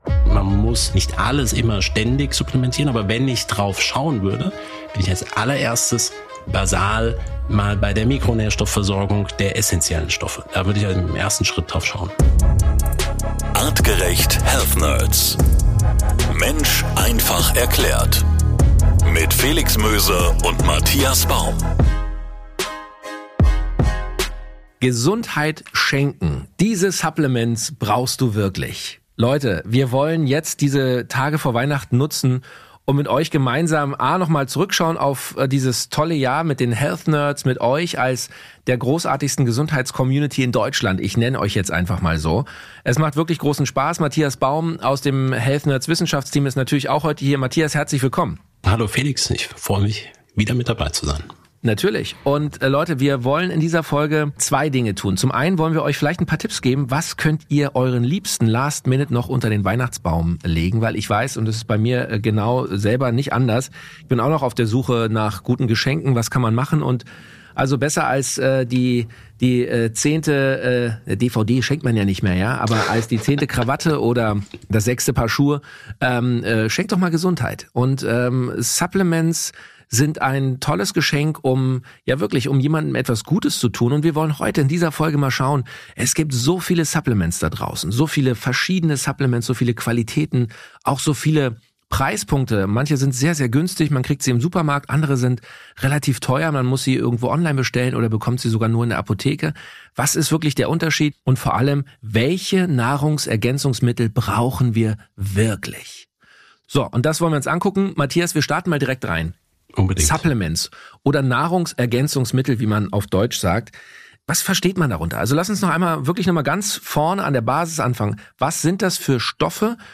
In unserem Interview